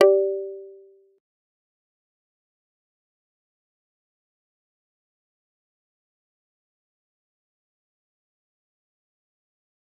G_Kalimba-G4-pp.wav